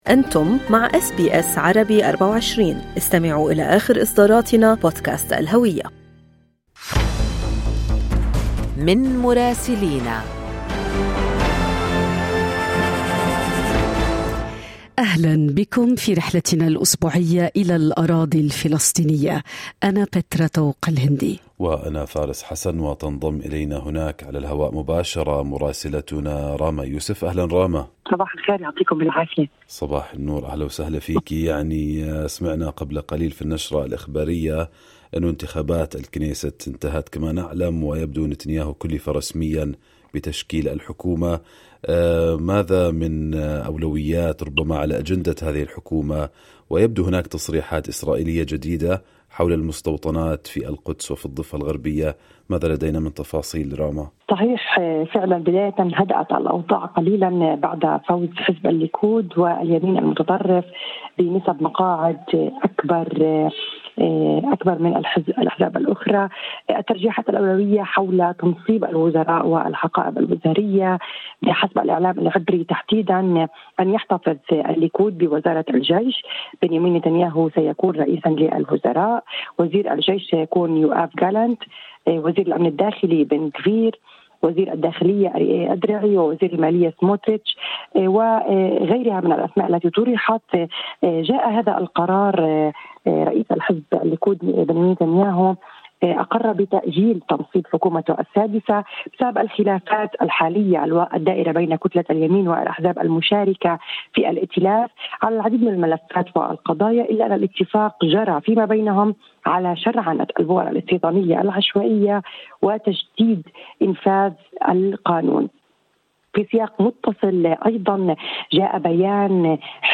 يمكنكم الاستماع إلى تقرير مراسلتنا في الأراضي الفلسطينية بالضغط على التسجيل الصوتي أعلاه.